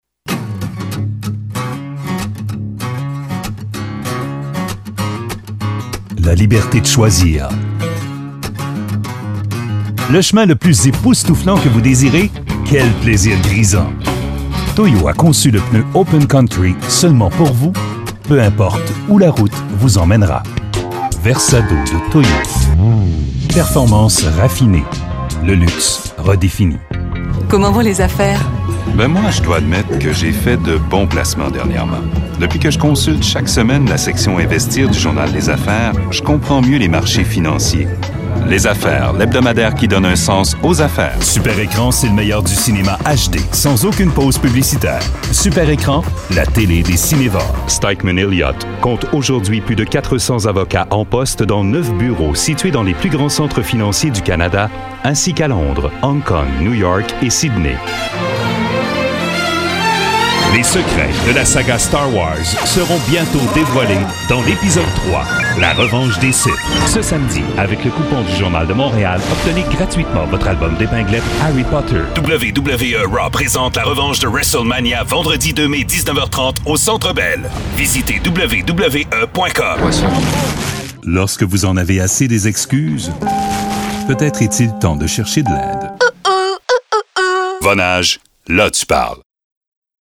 Male
French (Canadian)
A Voice...
Radio Commercials
All our voice actors have professional broadcast quality recording studios.